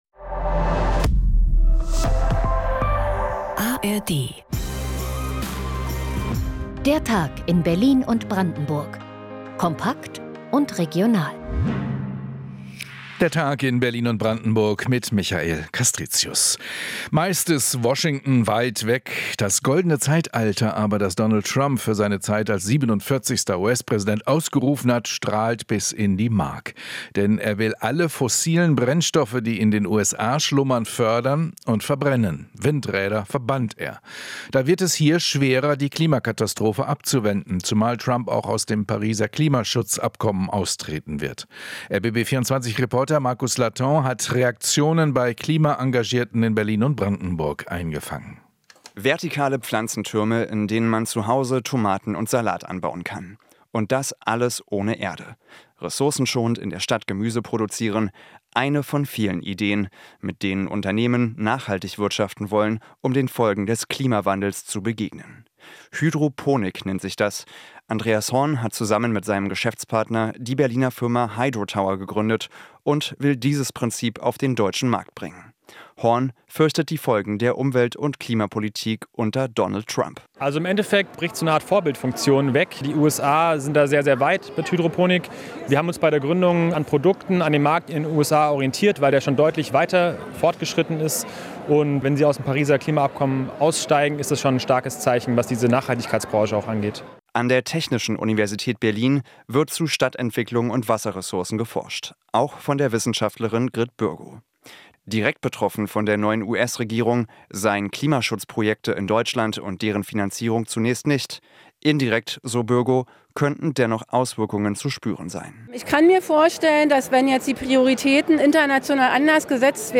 … continue reading 31 afleveringen # Nachrichten # Rbb24 Inforadio